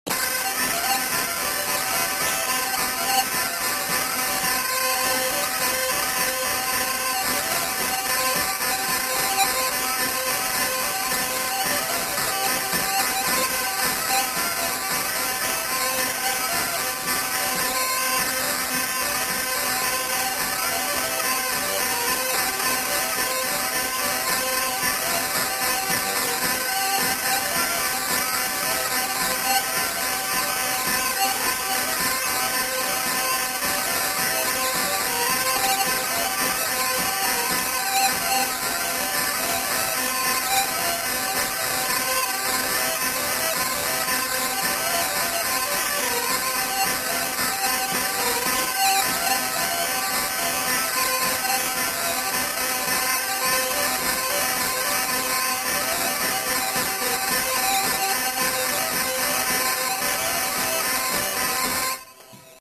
Aire culturelle : Gabardan
Lieu : Oeyreluy
Genre : morceau instrumental
Instrument de musique : vielle à roue
Danse : rondeau